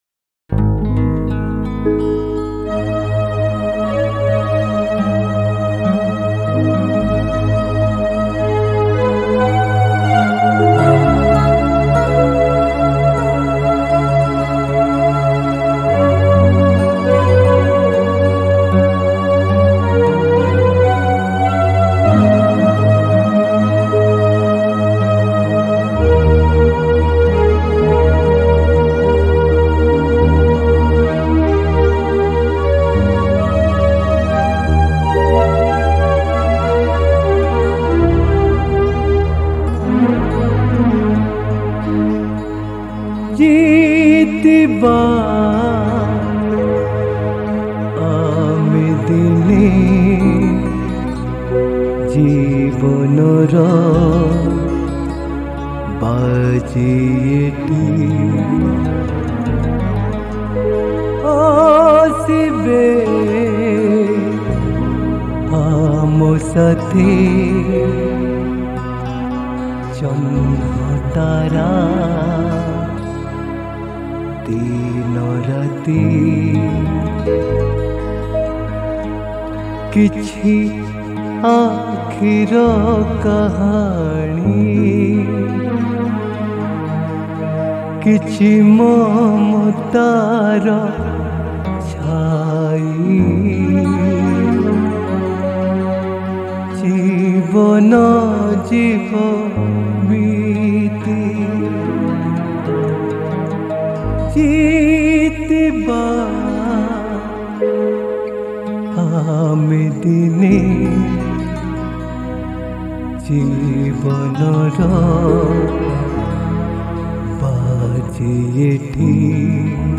Male Sad Version